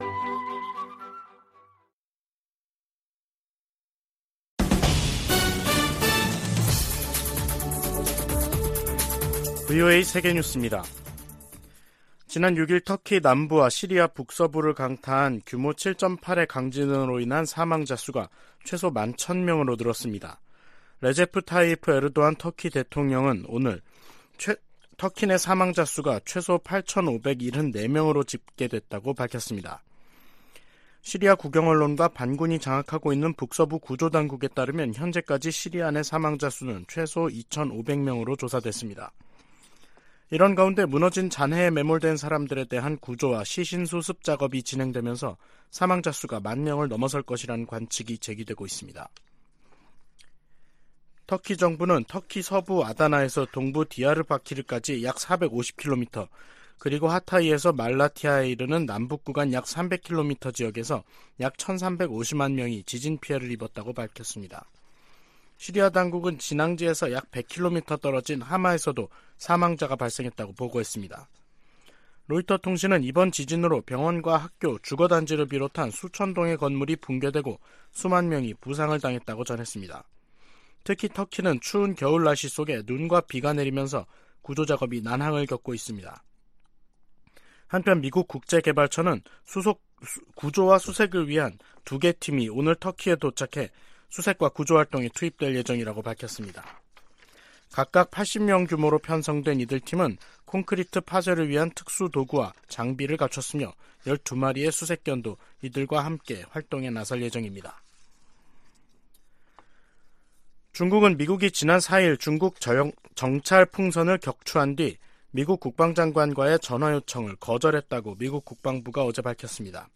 VOA 한국어 간판 뉴스 프로그램 '뉴스 투데이', 2023년 2월 8일 2부 방송입니다. 조 바이든 미국 대통령은 2일 국정연설을 통해 중국이 미국의 주권을 위협한다면 ‘우리는 나라를 보호하기 위해 행동할 것’이라고 말했습니다. 유엔이 국제적 긴장을 고조시키는 북한의 핵 개발과 미사일 발사에 우려한다는 입장을 거듭 확인했습니다.